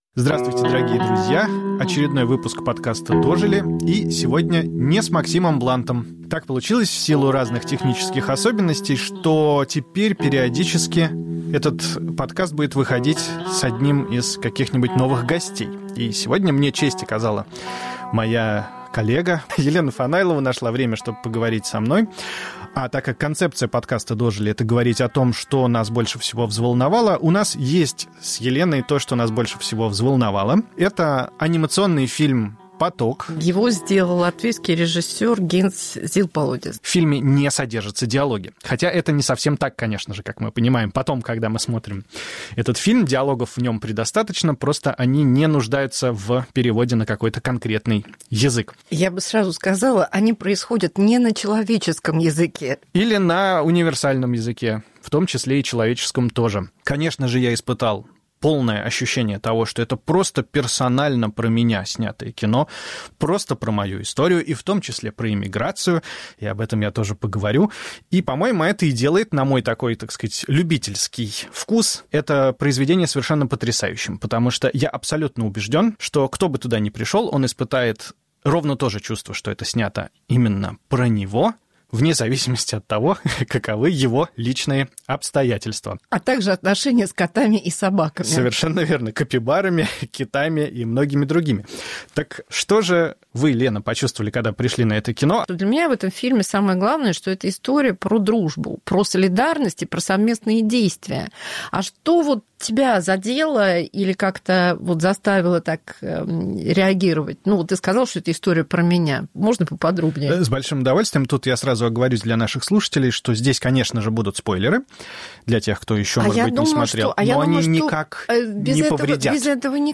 В рассуждениях о жизни и смерти, одиночестве и дружбе, взрослении, ведущие быстро понимают, что говорят они не мультипликационных животных, а о самих себе. Повтор эфира от 2 февраля 2025 года.